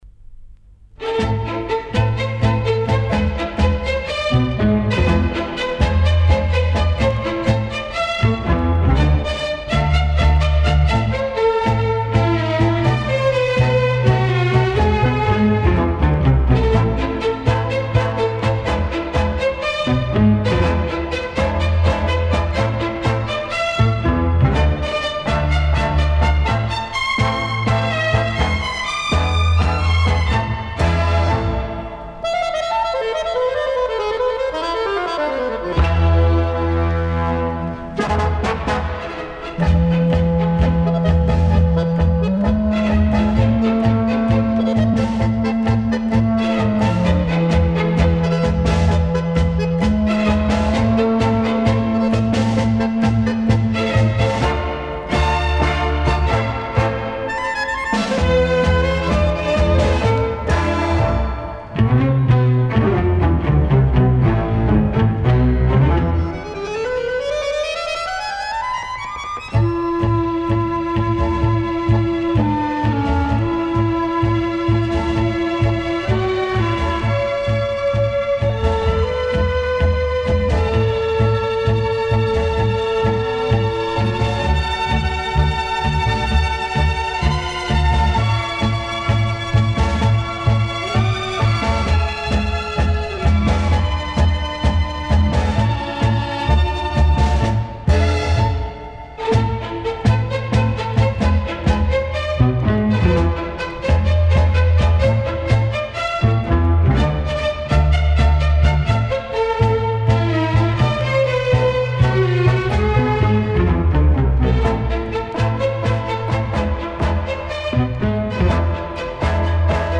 Genre:Latin